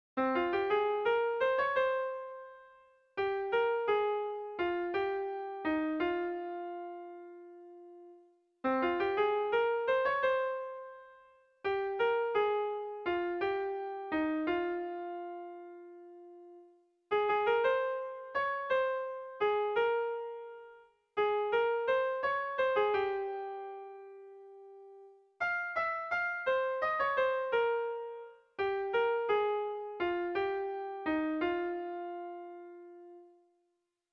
Air de bertsos - Voir fiche   Pour savoir plus sur cette section
AABA2